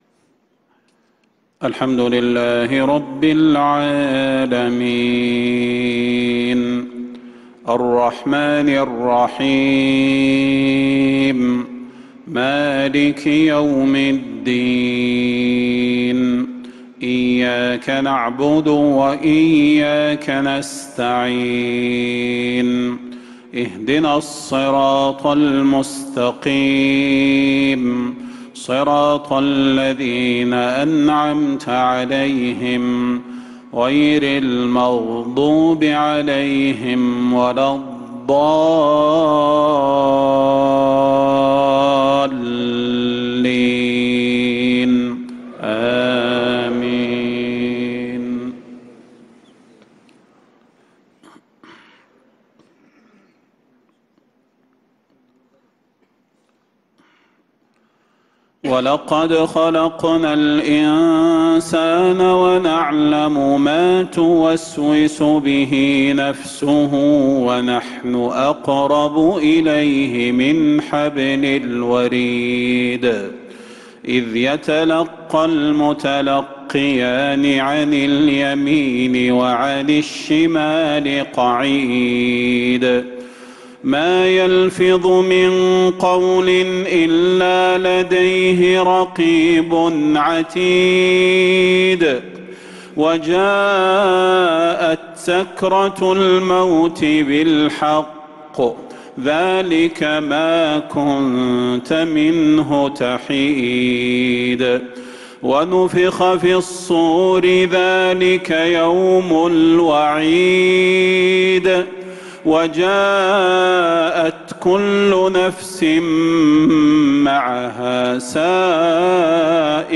عشاء الجمعة 7 صفر 1447هـ من سورة ق 16-35 | Isha prayer from Surat Qaf 1-8-2025 > 1447 🕌 > الفروض - تلاوات الحرمين